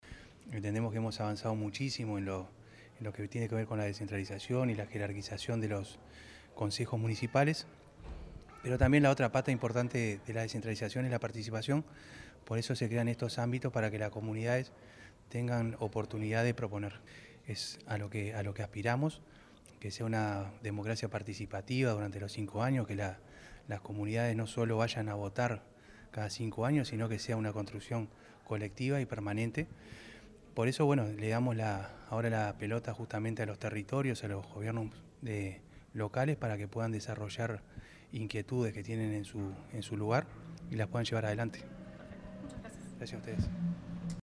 En la Sala Beto Satragni del Complejo Cultural Politeama se llevó a cabo el lanzamiento de los Fondos Participativos Municipales, cuyo objetivo es fortalecer la descentralización y la participación ciudadana en los 30 municipios canarios.
ruben_moreno_director_general_de_descentralizacion.mp3